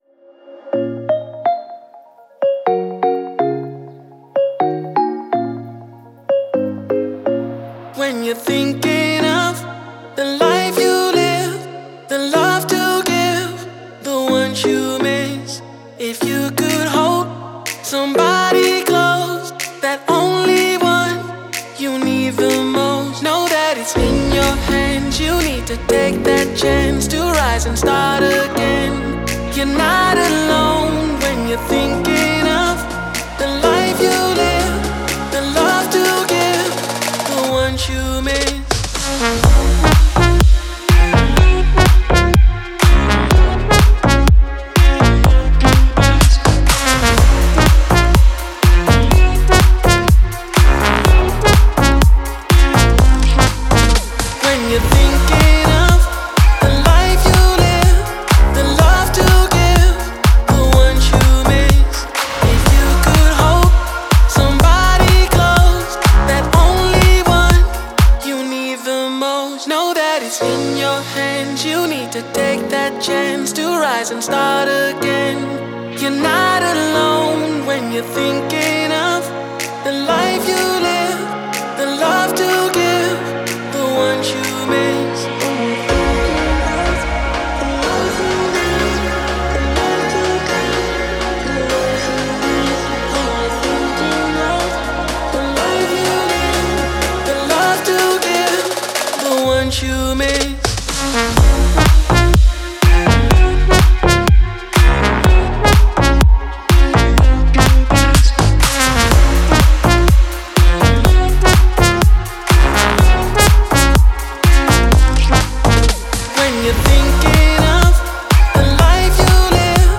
энергичная EDM-композиция